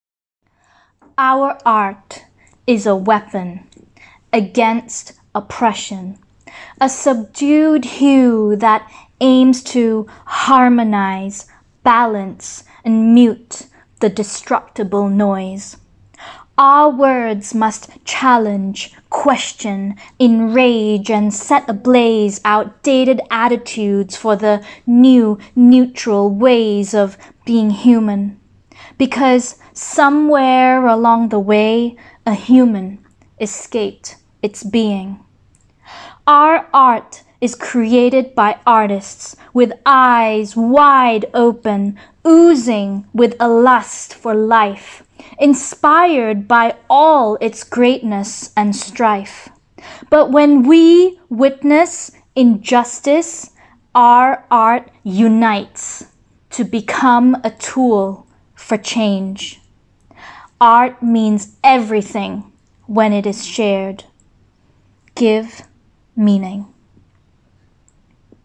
Poem